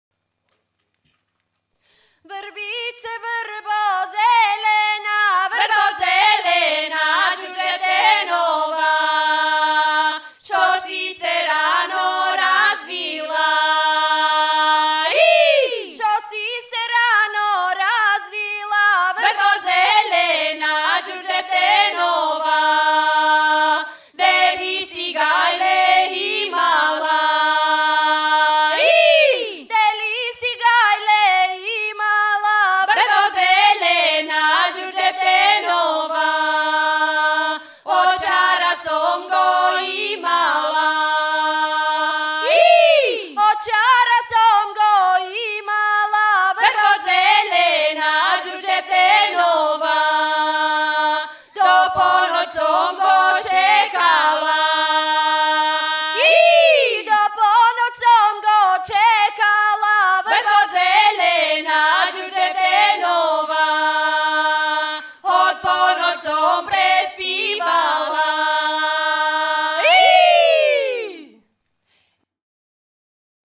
Ritmos de los Balcanes
una canción vocal   .